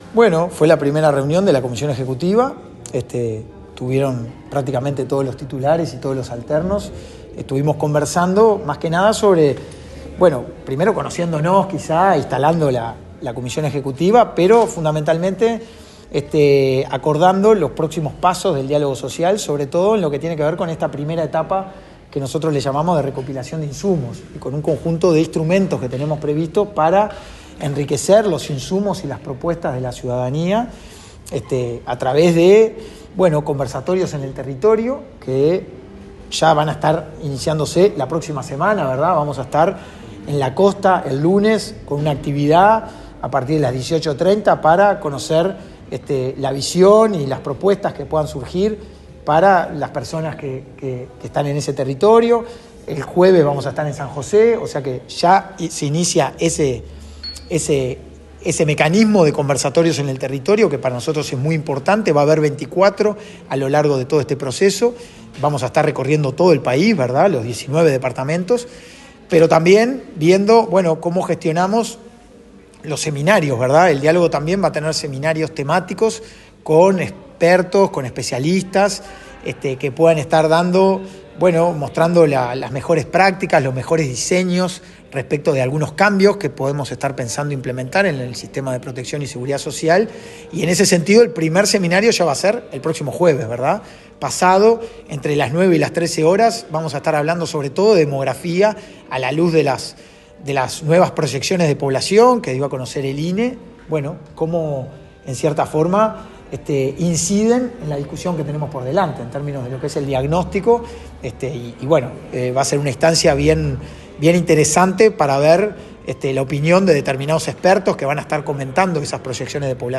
Tras la primera reunión de la Comisión Ejecutiva del Diálogo Social, el coordinador de ese ámbito, Hugo Bai, dialogó con la prensa.